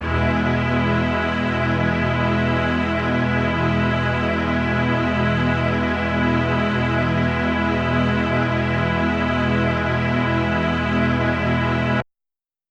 SO_KTron-Ensemble-Emaj.wav